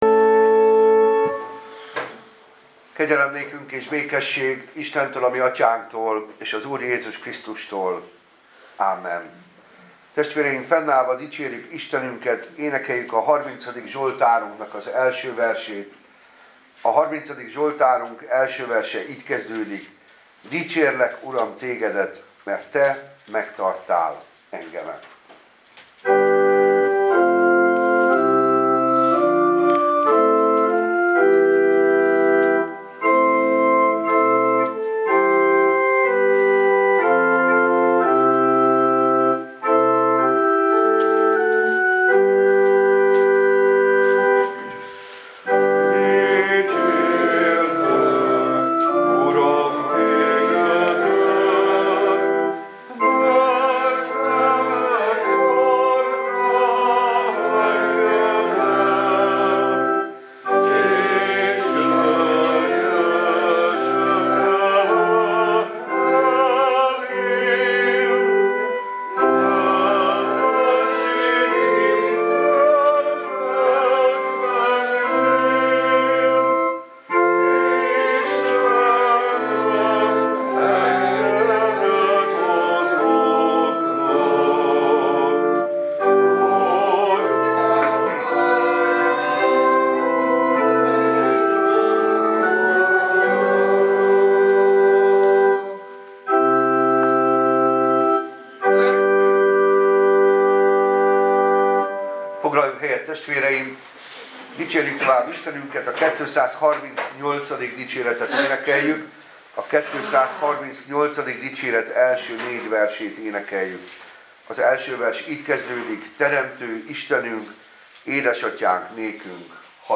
Passage: Jn 11, 45-52 Service Type: Igehirdetés